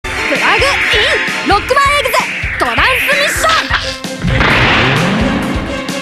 Considering that these two are voiced by females in Japan, their more male-toned voices in the English version is a big change.
In the Japanese version, Lan instead says "